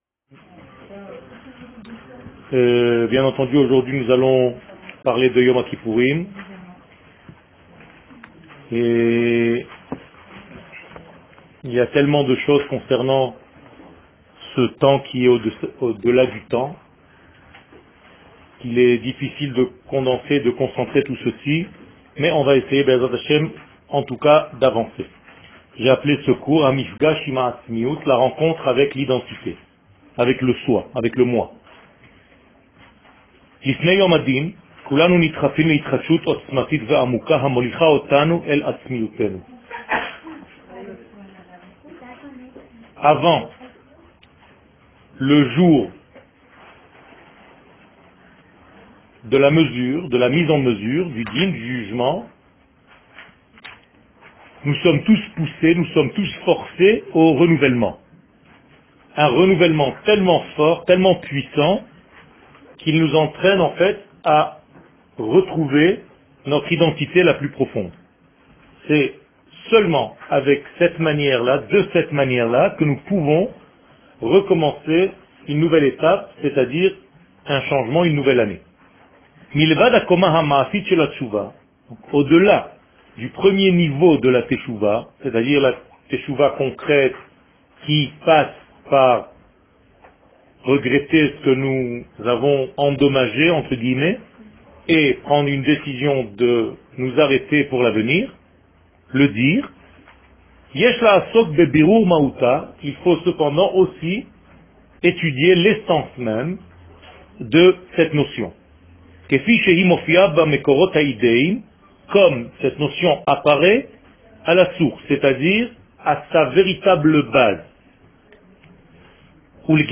Yom Hakipourim 2016 Fetes/Calendrier שיעור מ 09 אוקטובר 2016 01H 06MIN הורדה בקובץ אודיו MP3 (11.39 Mo) הורדה בקובץ אודיו M4A (7.89 Mo) TAGS : Teshouva Yom Hakipourim Torah et identite d'Israel שיעורים קצרים